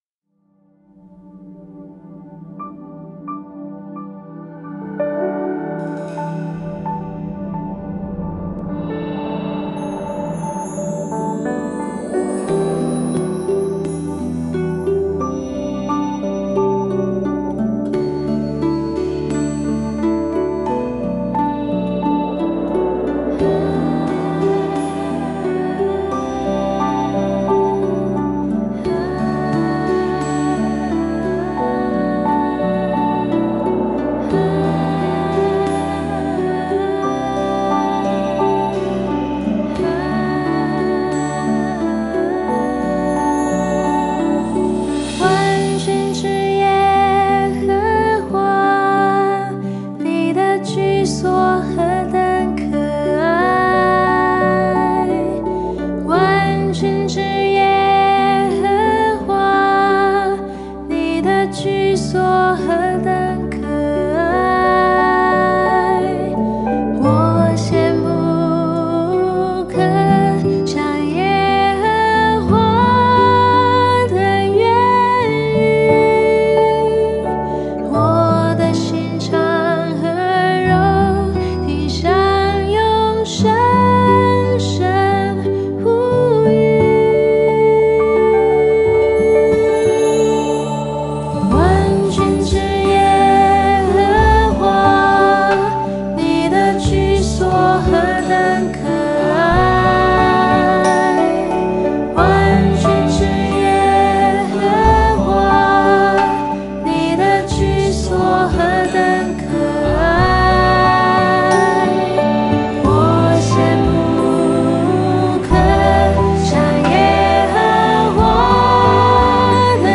豎琴